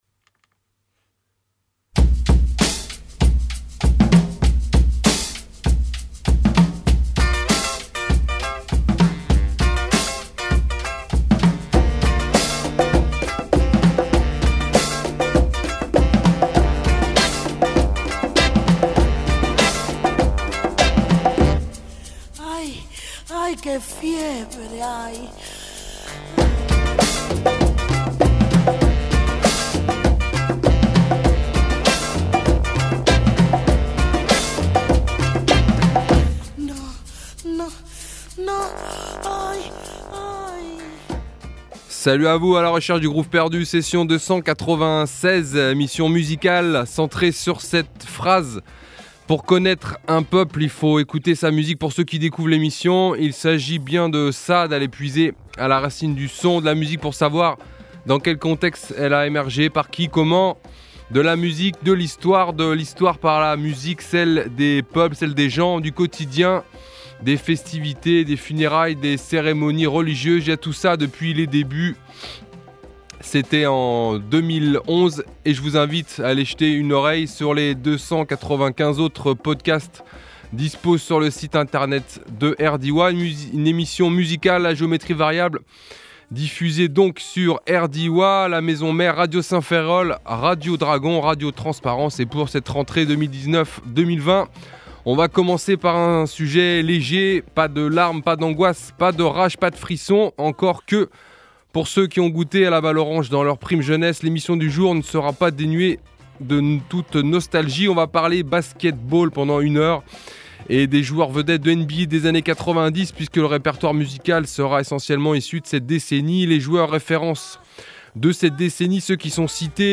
blues , hip-hop , musique , rock